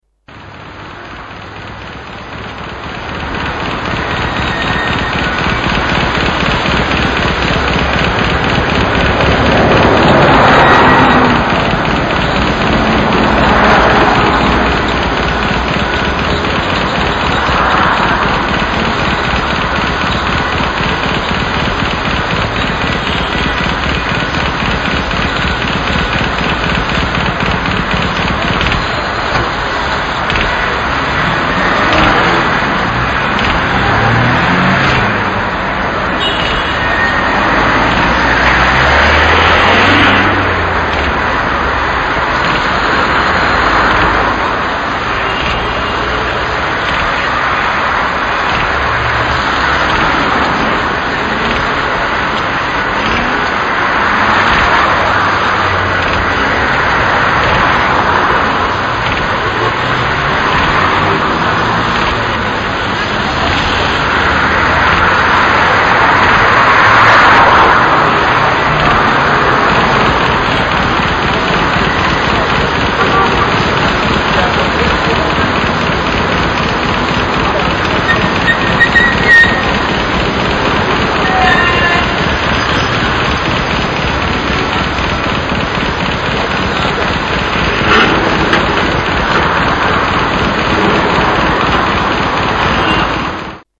Intonarumori"1921 Niet alleen de beweging, bijvoorbeeld op straat, willen schilderen, maar ook het lawaai dat op straat is te horen.......
Russolo Intonarumori 1921.mp3